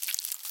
Sound / Minecraft / mob / silverfish / step2.ogg
step2.ogg